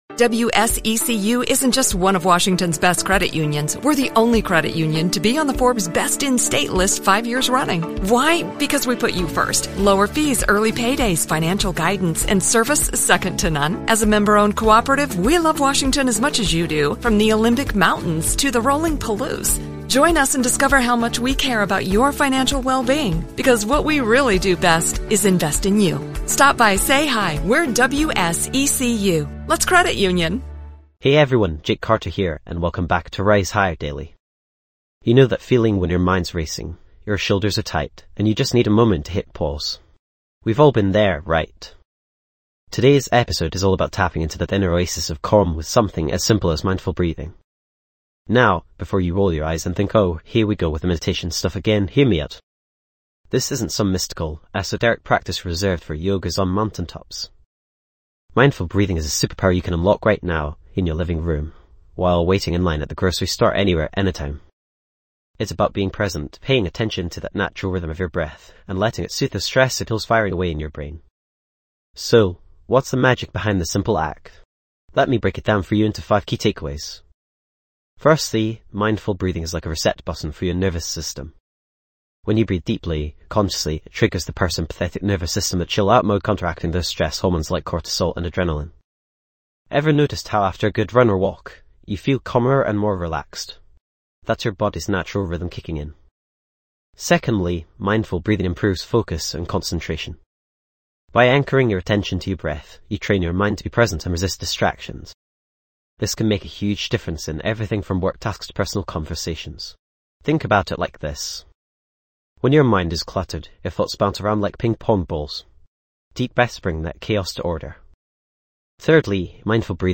- Experience instant stress relief with guided exercises
This podcast is created with the help of advanced AI to deliver thoughtful affirmations and positive messages just for you.